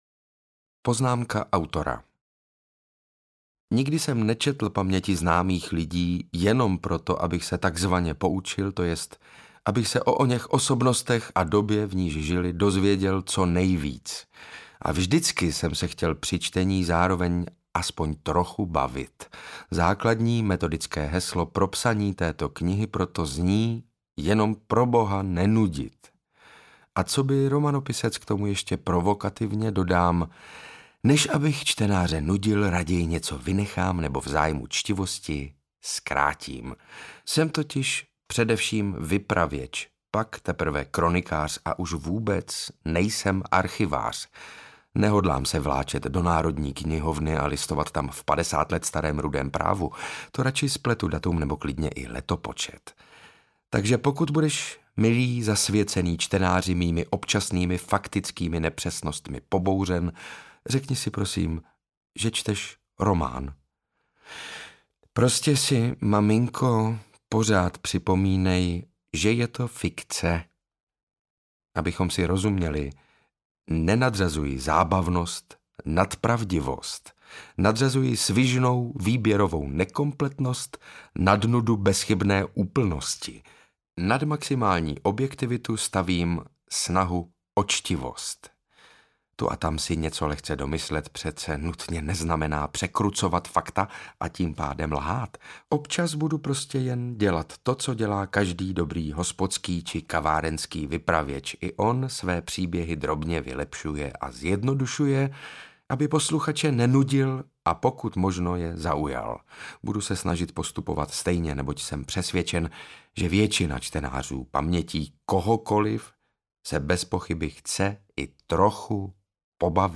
Audio knihaDěravé paměti - Šedesát báječných let pod psa
Ukázka z knihy
• InterpretSaša Rašilov, Martina Hudečková